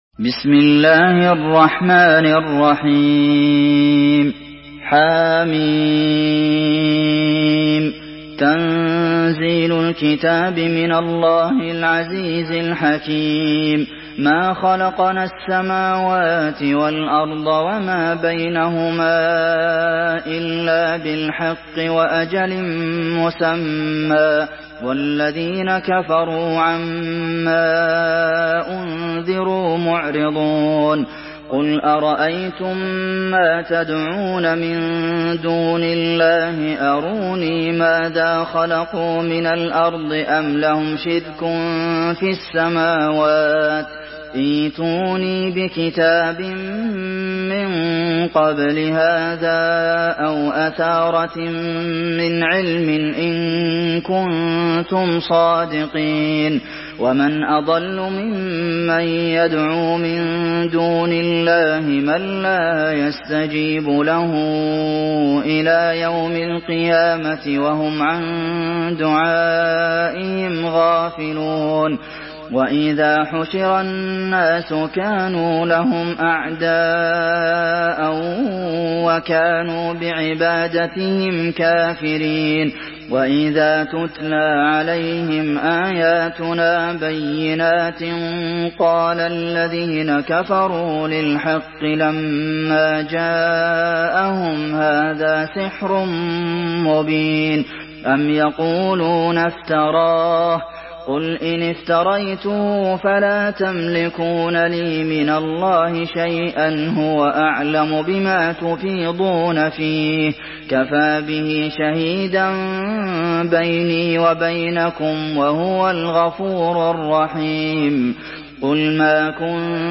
Surah Al-Ahqaf MP3 in the Voice of Abdulmohsen Al Qasim in Hafs Narration
Murattal Hafs An Asim